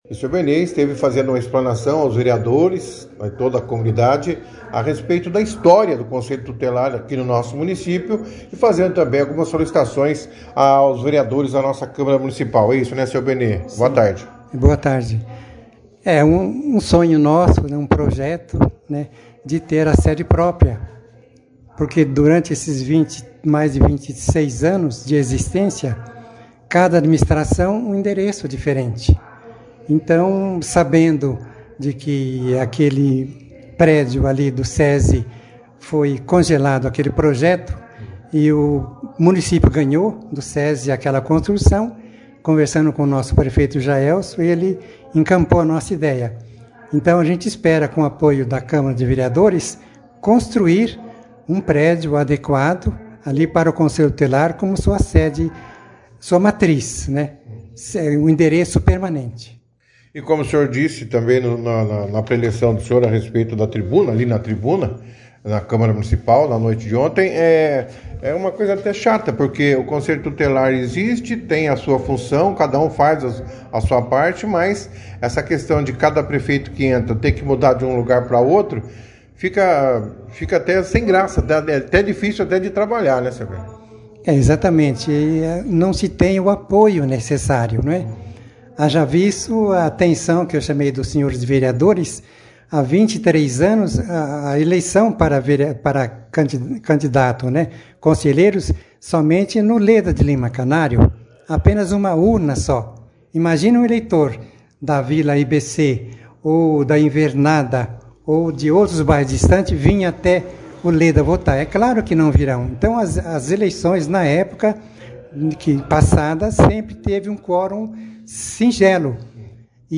A sessão foi destaque na 2ª edição do jornal Operação Cidade desta terça-feira, 11/04